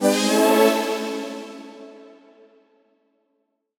FR_ZString[up]-A.wav